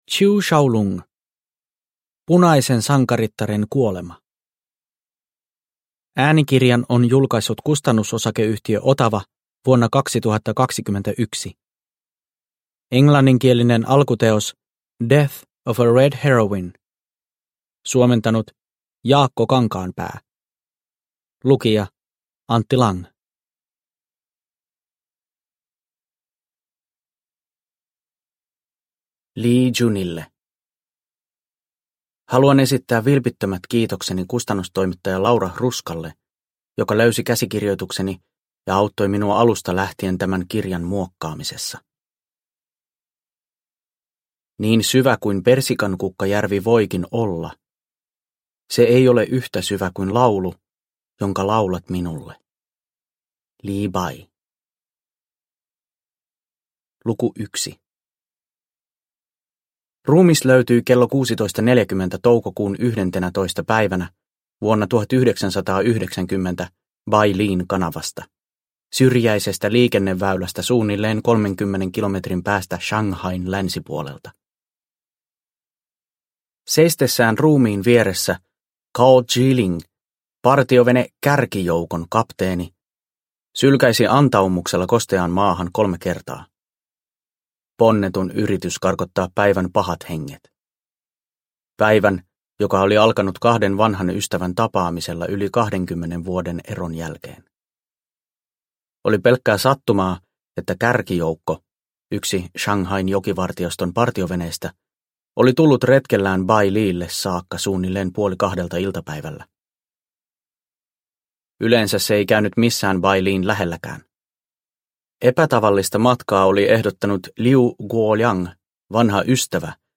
Punaisen sankarittaren kuolema – Ljudbok – Laddas ner